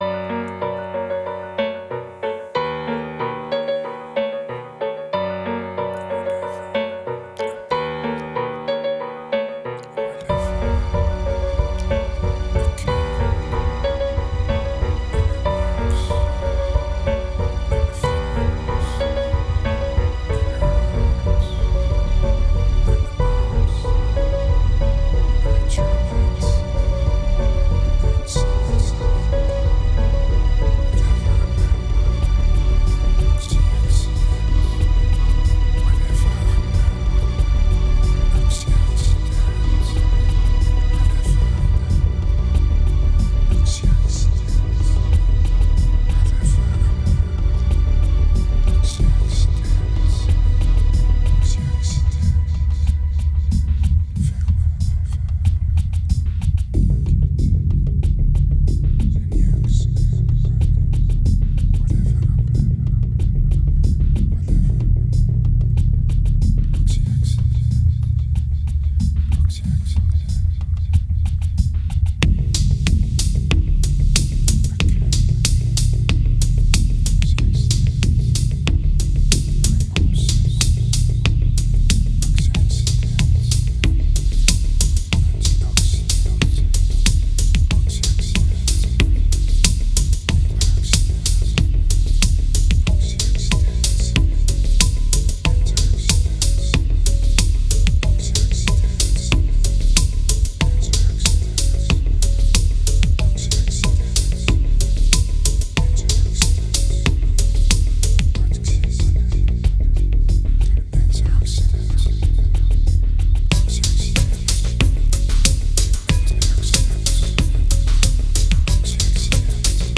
2306📈 - 4%🤔 - 93BPM🔊 - 2010-03-11📅 - -124🌟